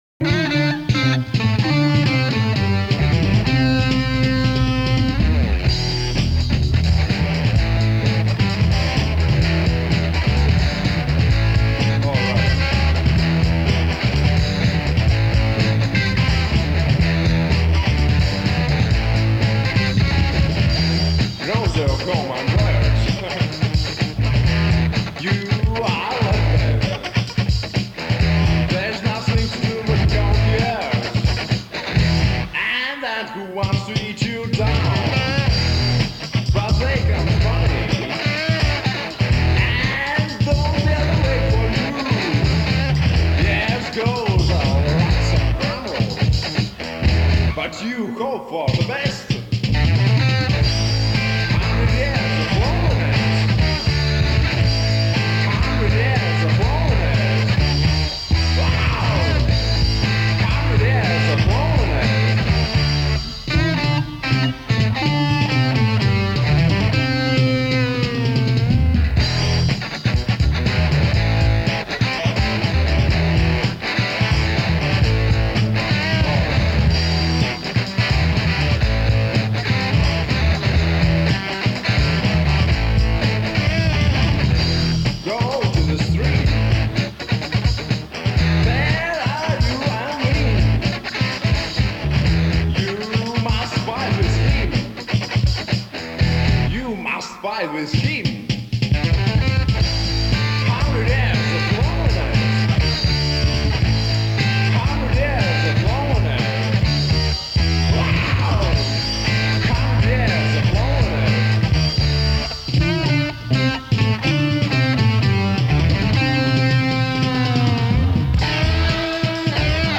Dziesmas
Mūzikas ieraksts
AVOX